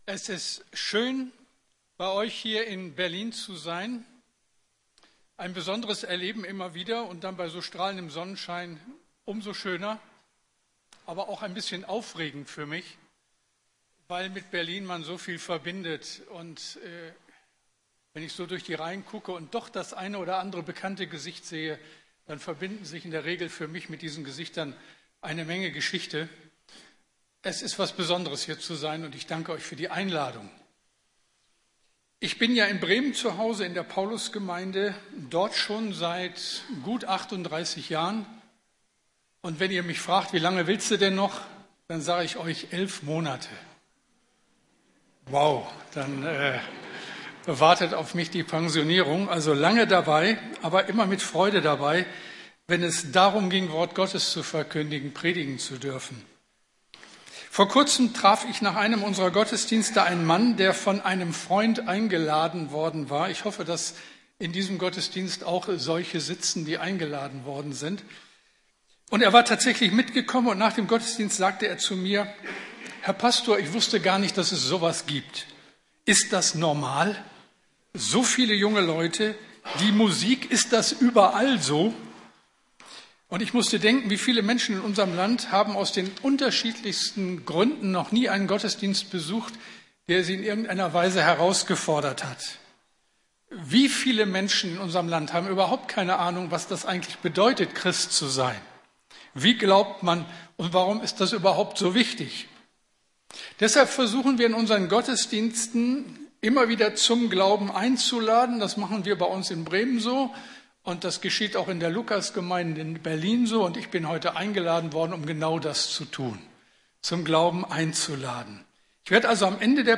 Wie könnte ich sagen, es gibt keinen Gott? ~ Predigten der LUKAS GEMEINDE Podcast